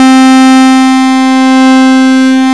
MINI LEAD 2.wav